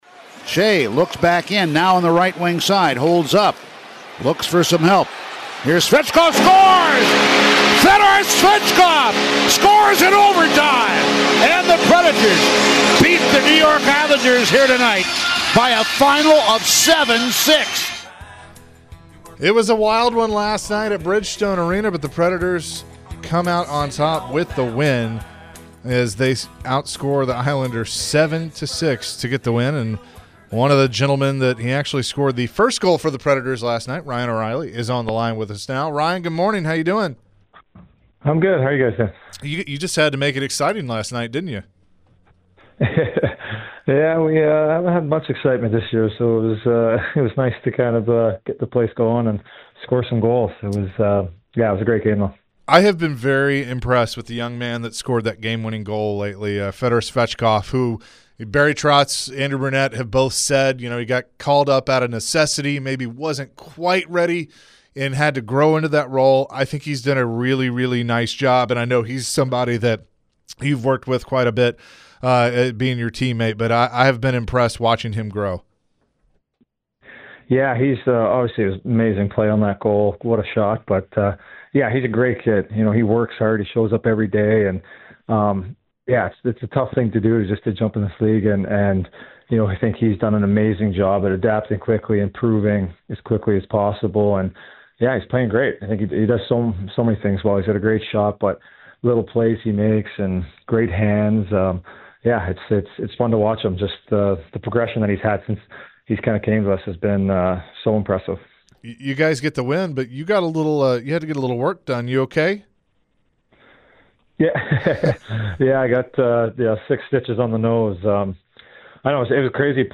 The guys chatted with Nashville Predators Ryan O’Reilly after last night’s 7-6 OT winner! Ryan discussed the great performance by some of the youngsters on the roster!